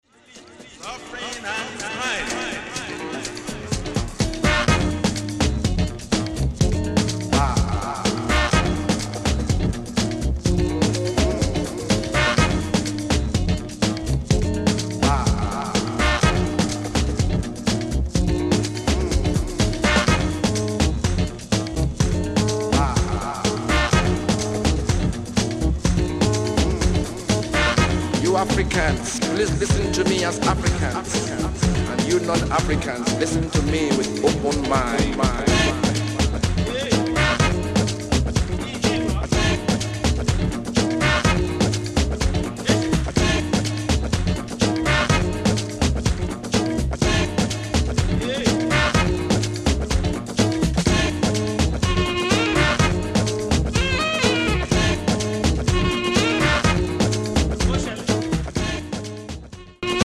Re-Edits